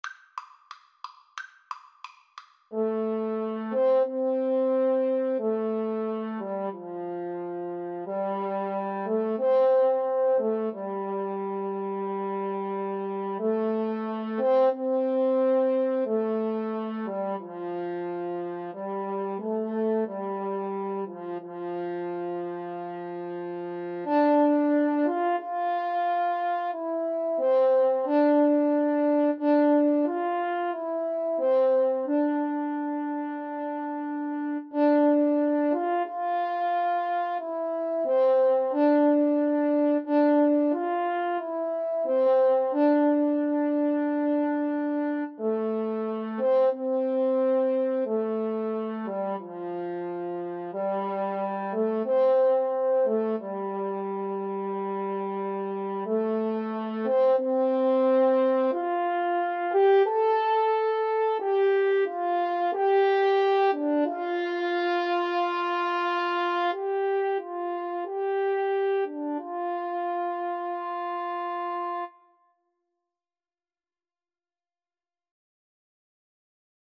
F major (Sounding Pitch) C major (French Horn in F) (View more F major Music for French Horn Duet )
=180 Largo
French Horn Duet  (View more Easy French Horn Duet Music)
Classical (View more Classical French Horn Duet Music)